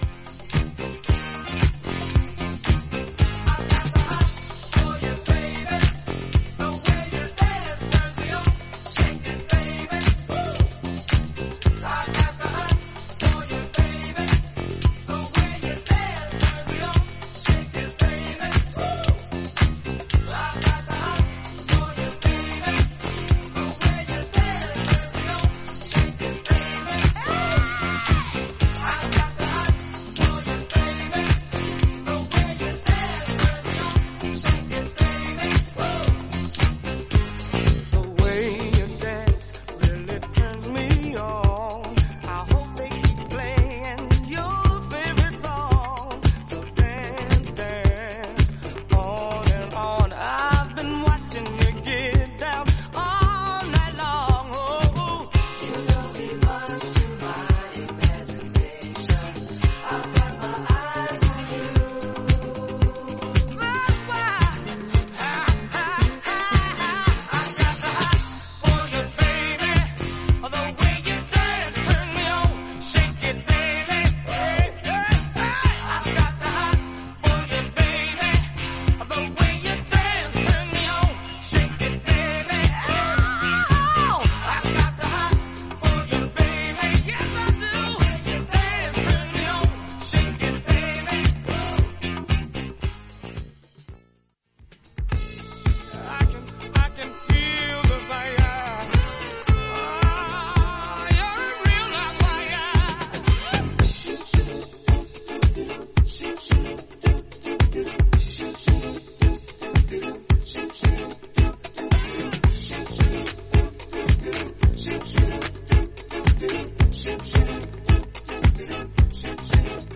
洗練されたアレンジの
ディスコ・ファンク・チューン